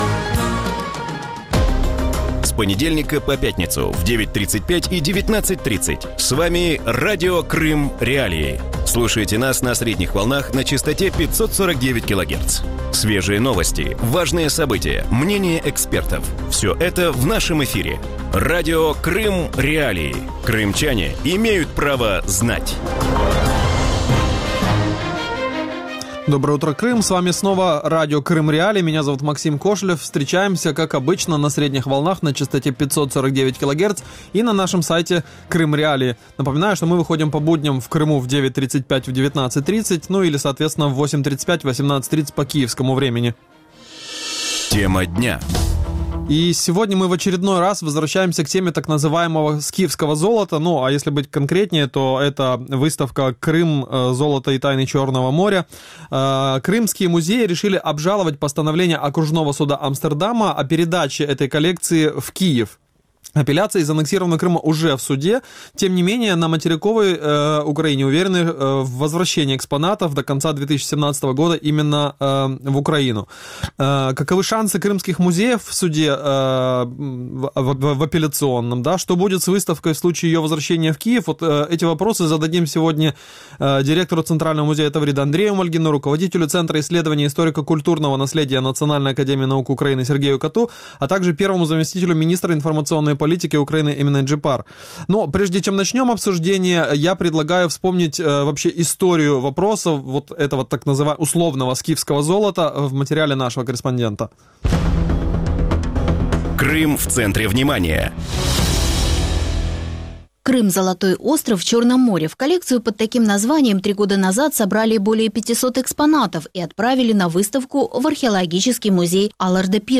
В утреннем эфире Радио Крым.Реалии говорят о решении крымских музеев обжаловать постановление окружного суда Амстердама о передаче коллекции «скифского золота» в Киев. Апелляция из аннексированного Крыма уже в суде, а на материковой Украине уверены в возвращении экспонатов до конца 2017 года.